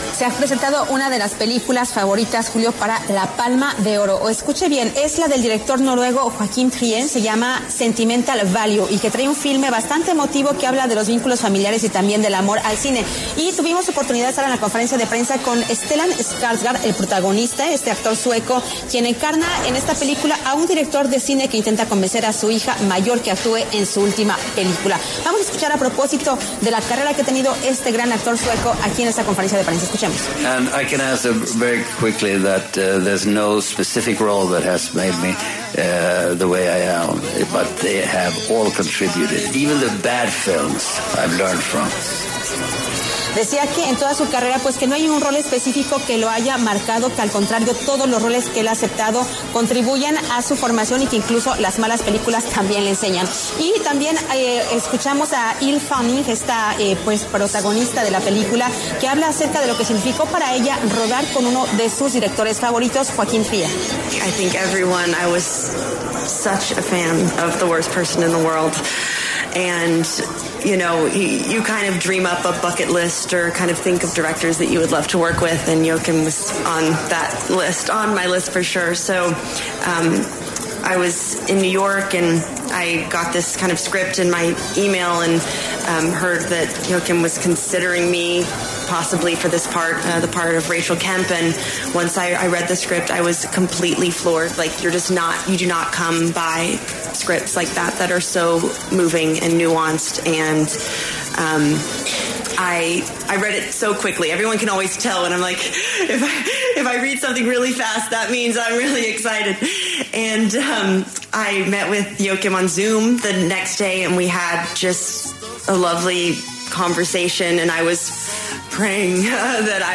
Desde el Festival de Cannes 2025, La W estuvo en la rueda de prensa de Stellan Skarsgård, protagonista de la película ‘Sentimental Value’, un filme que resalta los vínculos familiares y el amor al cine.